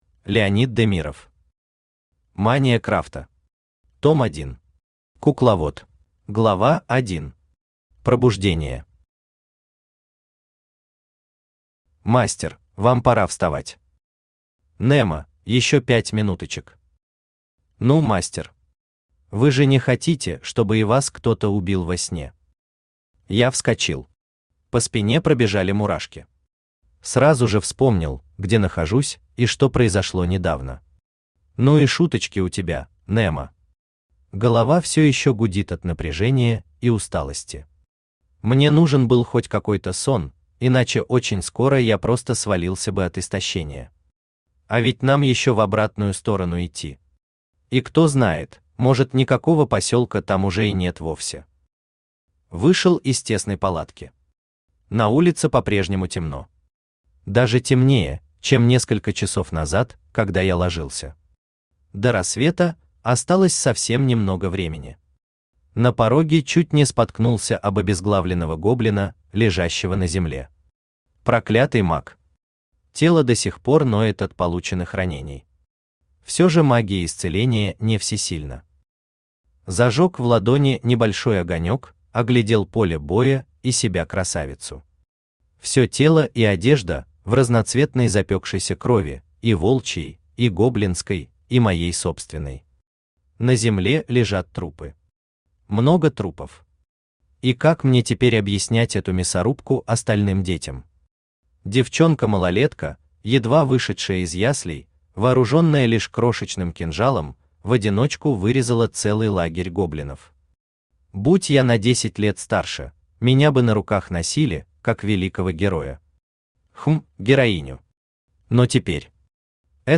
Аудиокнига Мания крафта. Том 1. Кукловод | Библиотека аудиокниг
Кукловод Автор Леонид Демиров Читает аудиокнигу Авточтец ЛитРес.